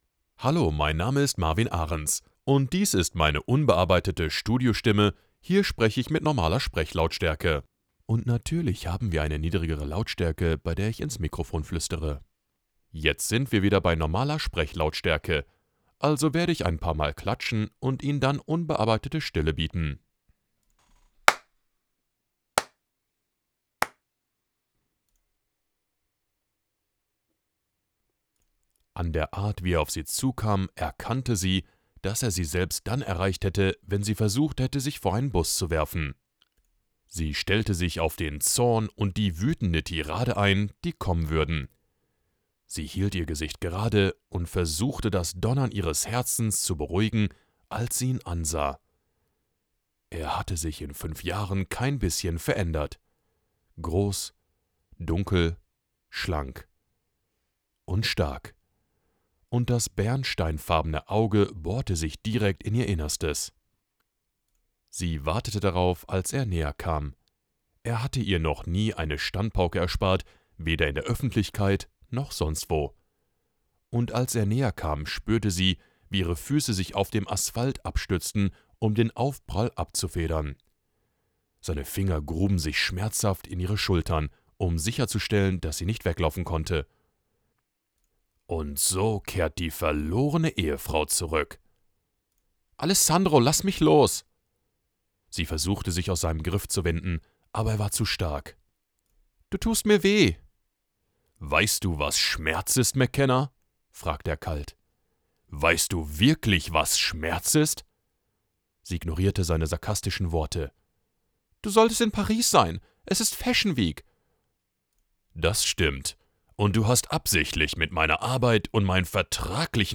Voice Over Demos
Narration
Clear, compelling storytelling for every format